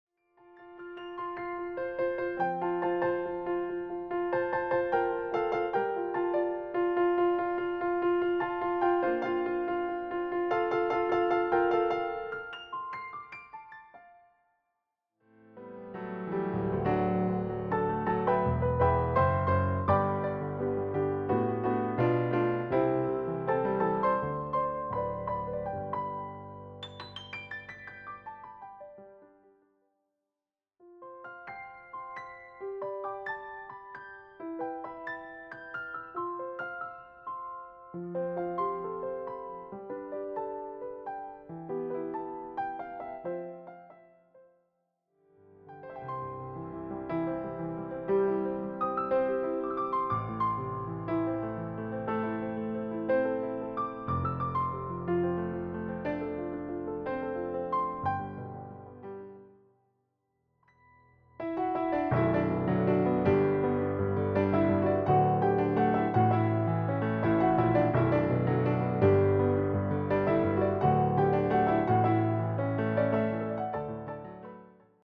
clear and expressive piano arrangements
setting a relaxed and welcoming tone right away.